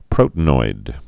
(prōtn-oid, prōtē-noid)